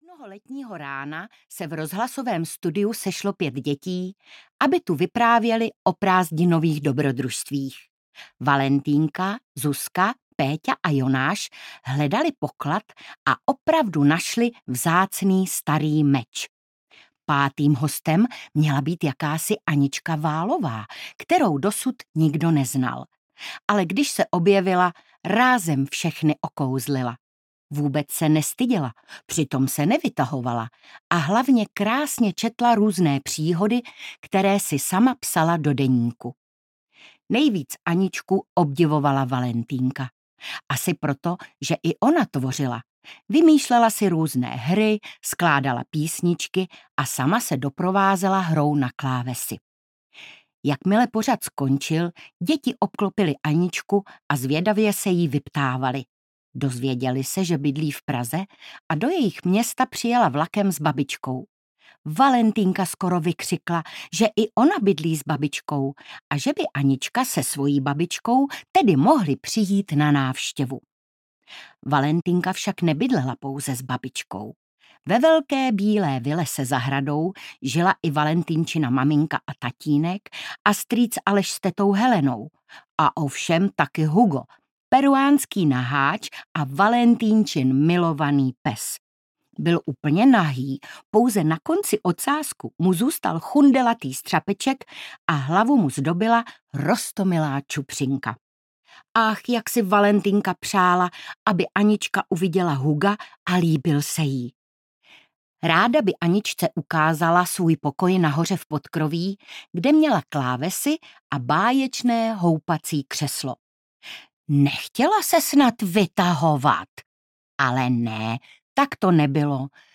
Valentýnka na Šumavě audiokniha
Ukázka z knihy
• InterpretIvana Andrlová